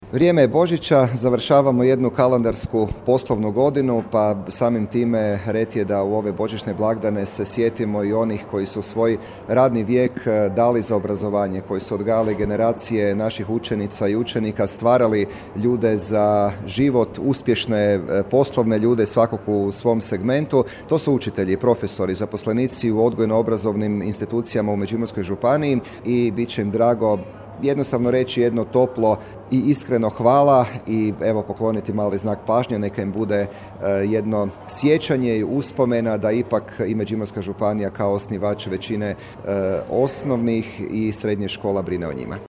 Međimurska županija: prijem za umirovljene djelatnike u obrazovanju, 2021
Upravo su kraj godine i božićni blagdani najbolja prilika da im se zahvali na generacijama djece koju su odškolovali i nemjerljivom doprinosu koji su dali međimurskom školstvu, rekao je župan Matija Posavec, a prenosimo iz našeg informativnog programa.